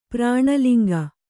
♪ prāṇa liŋga